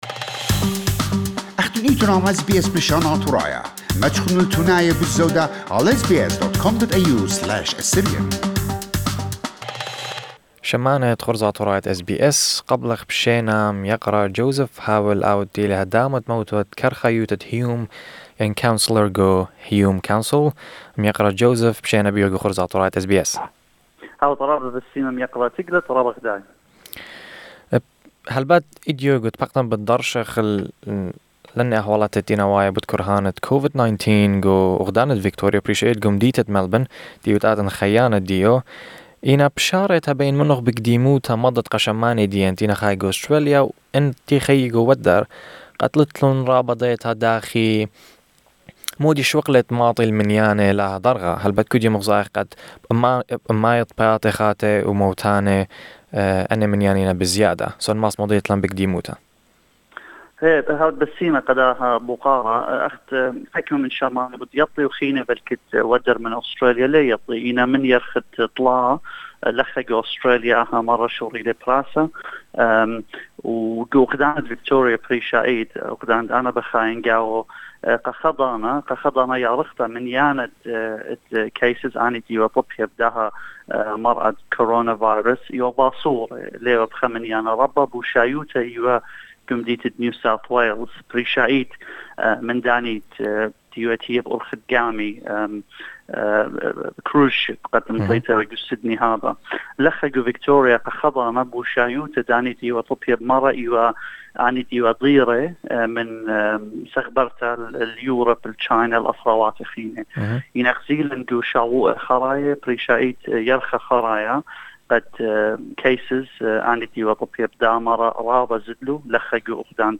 The ongoing increase of Covid-19 cases in Victoria is causing the government to introduce new restrictions. Joseph Haweil, councillor at Hume city council and resident of Victoria outlined to us the new restrictions and the effort from the government to stop the crisis.
Mr. Joseph began the interview by briefing the audience about how the current situation escalated in this quick manner.